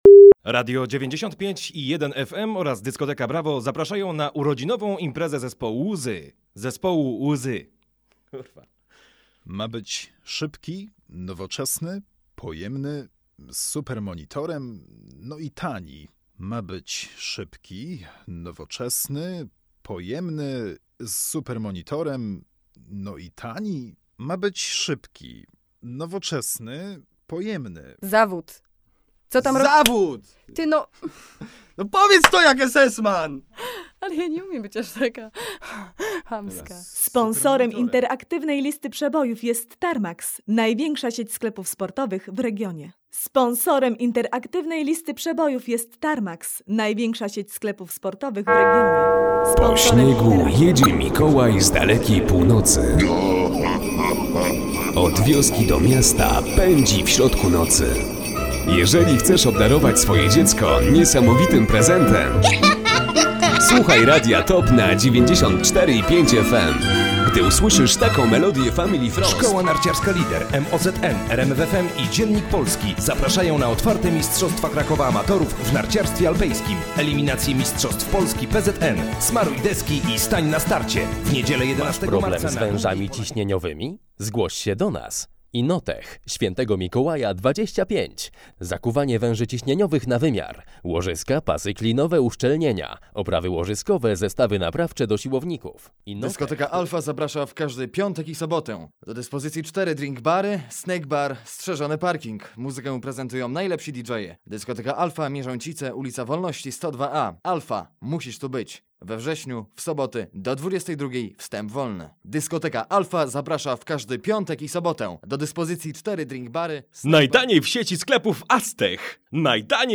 mikrofonika_lektorzy.mp3